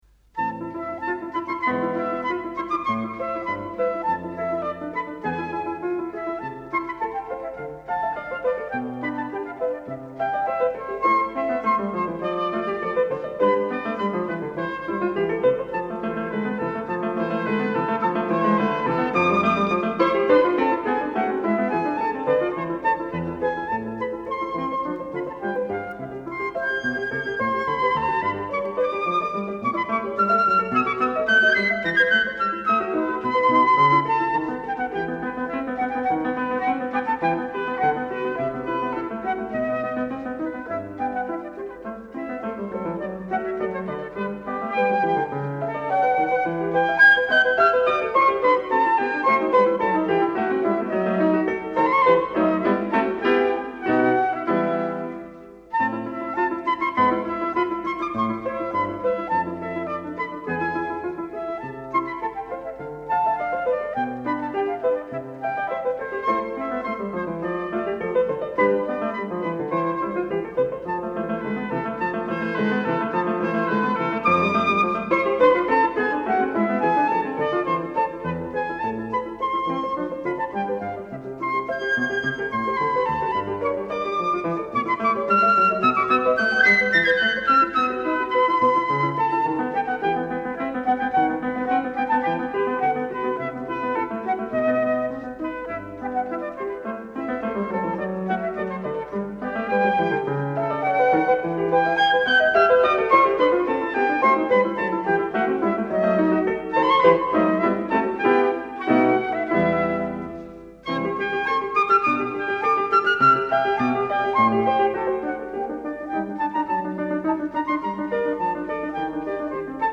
La série de ce jour est consacrée à Emil Gilels, pianiste très présent dans les pages et notules de ce blog –j’en ai plus d’une centaine de disques sur mes étagères-, mais dans des oeuvres que je ne côtoie que très épisodiquement, issues de différents coffrets anthologiques.
Globalement, dans ces années-là, les prises de son soviétiques sont la plupart du temps moins bonnes que celles réalisées à la même époque en Europe de l’ouest, mais l’ensemble reste d’une monophonie convenable.